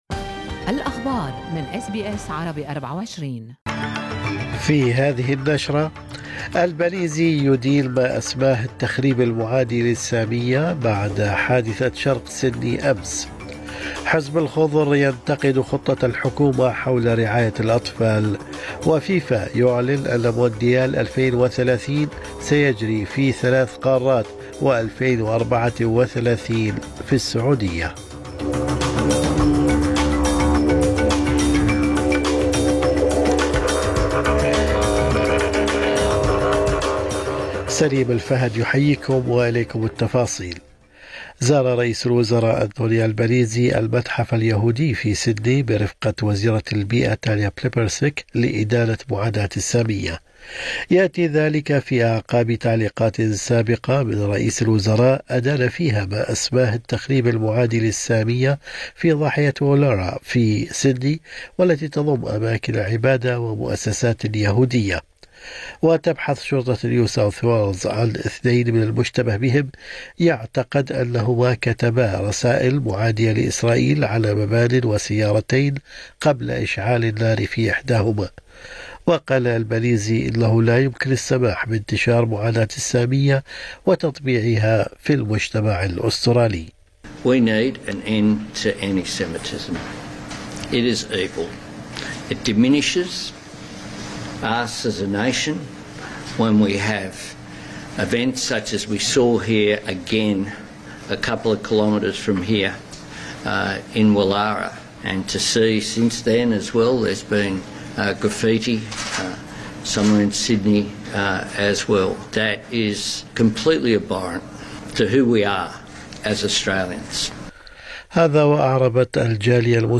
نشرة أخبار الصباح 12/12/2024